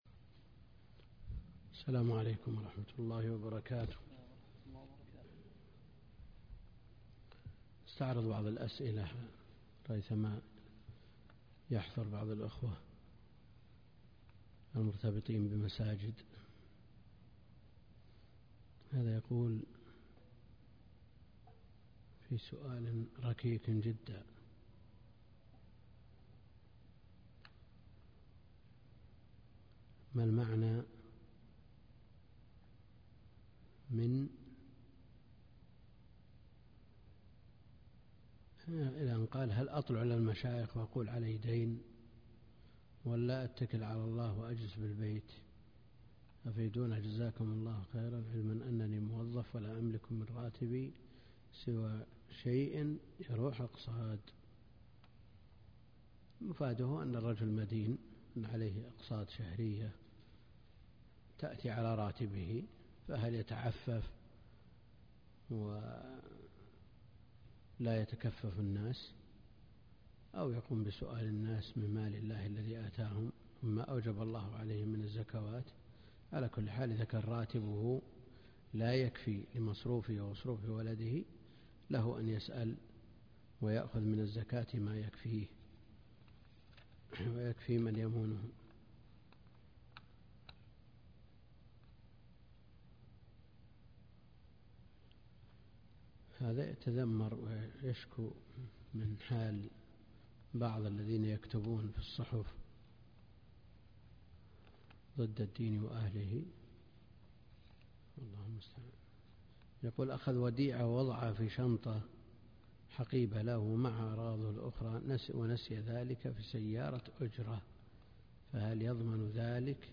الدرس (26) كتاب البيوع من بلوغ المرام - الدكتور عبد الكريم الخضير